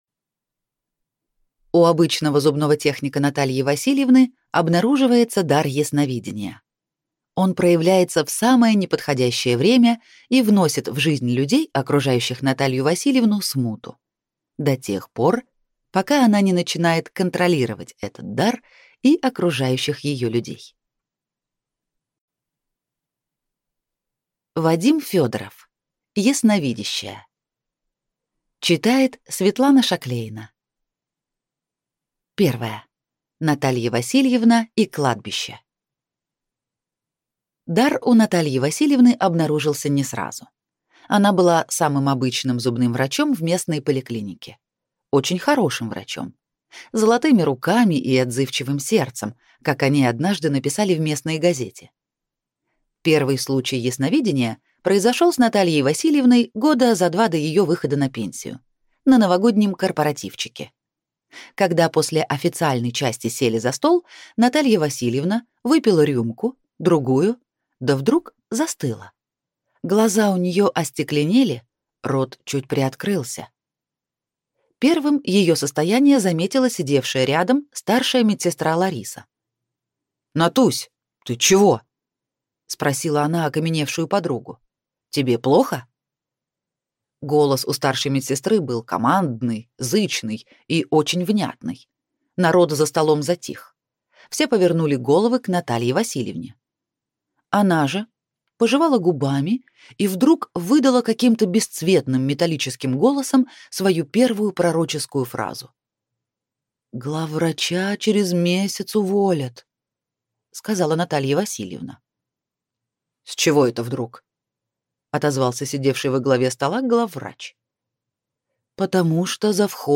Аудиокнига Ясновидящая | Библиотека аудиокниг
Прослушать и бесплатно скачать фрагмент аудиокниги